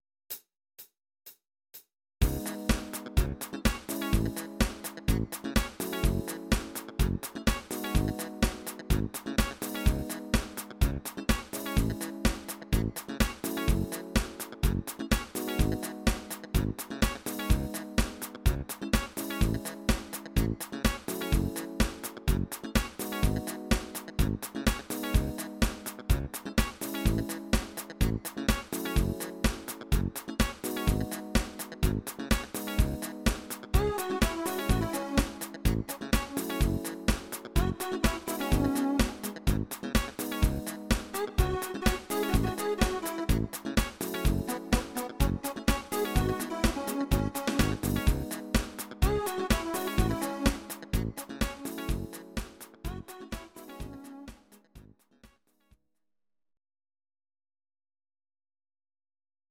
Audio Recordings based on Midi-files
Pop, Disco, 2000s